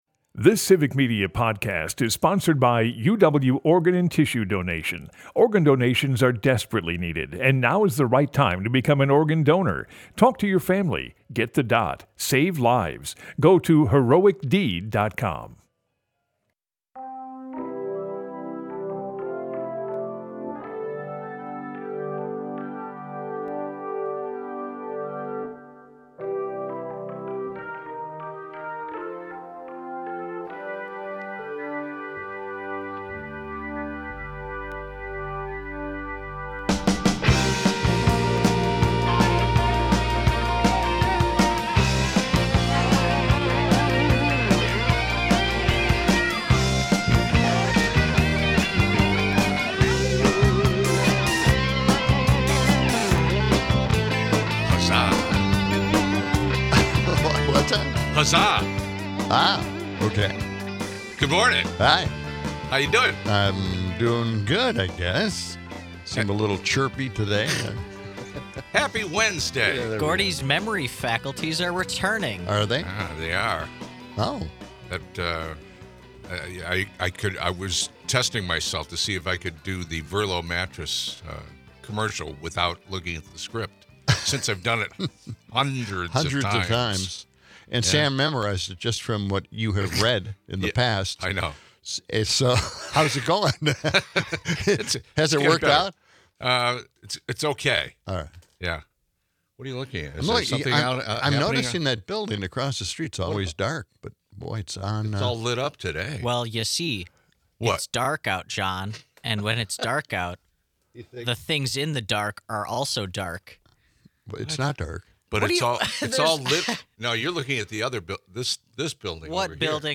Broadcasts live 6 - 8am weekdays in Madison.
Closing out, we hear the President-Elect sing about his love for farm life, and do some mental gymnastics to understand MTG's all-or-nothing approach to Trump's administration.